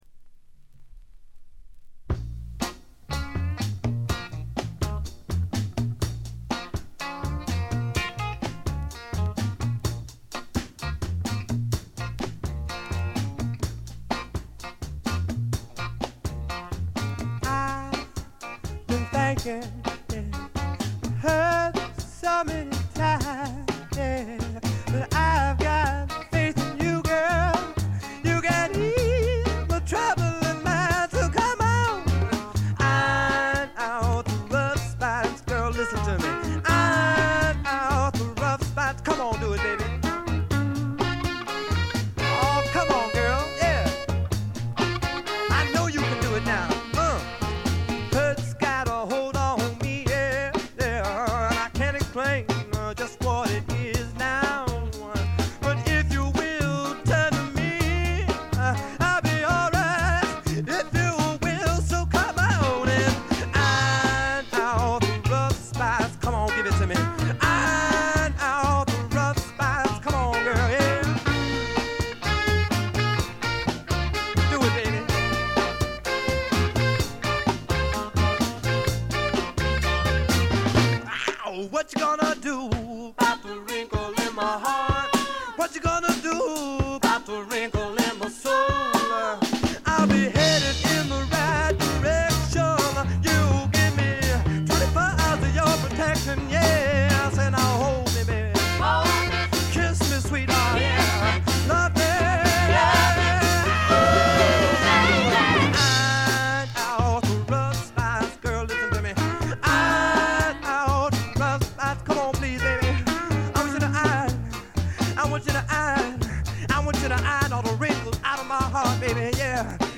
ロックとソウル境界線を行き来する傑作です。
試聴曲は現品からの取り込み音源です。